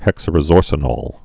(hĕksəl-rĭ-zôrsə-nôl, -nōl, -nŏl)